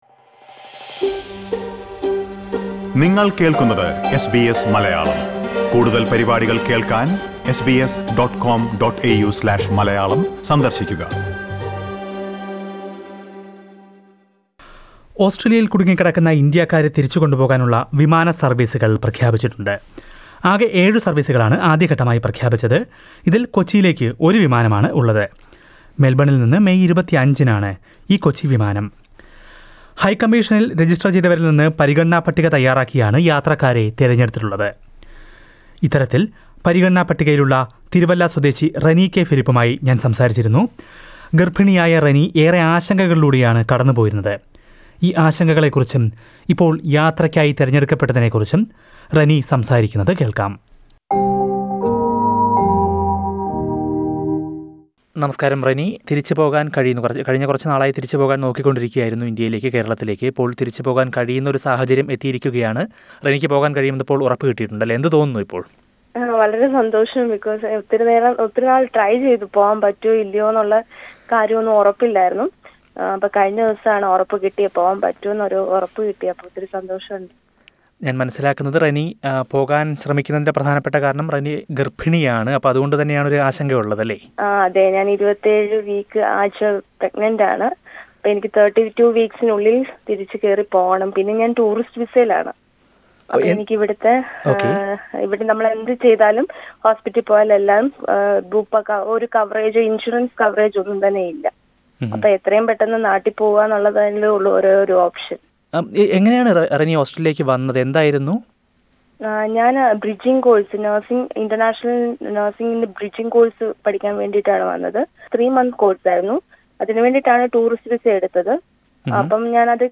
LISTEN TO Relieved to fly back home, says Malayalee woman after shortlisted for Air India repatriation flight SBS Malayalam 10:53 Malayalam Share